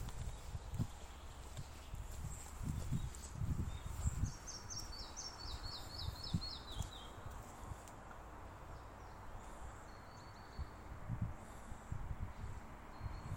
Mazais mušķērājs, Ficedula parva
Administratīvā teritorijaRīga
StatussDzirdēta balss, saucieni